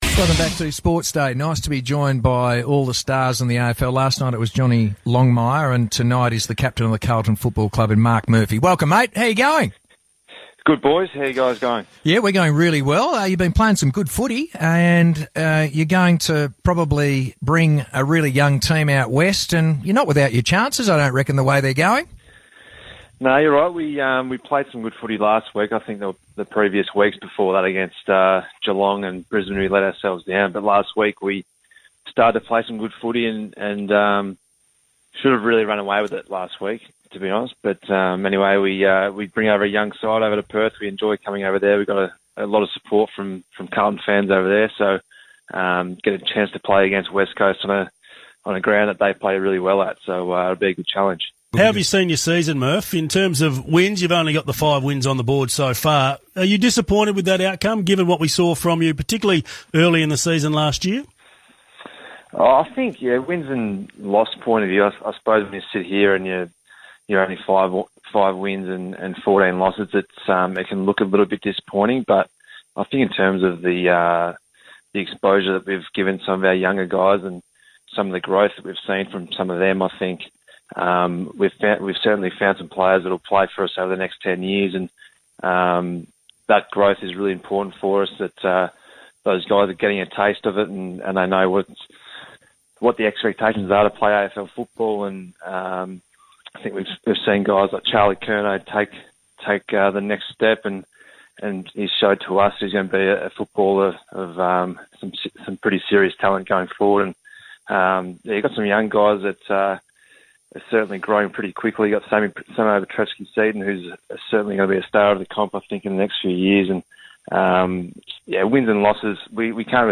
Carlton captain Marc Murphy speaks to Perth station 6PR ahead of the Blues' clash with West Coast.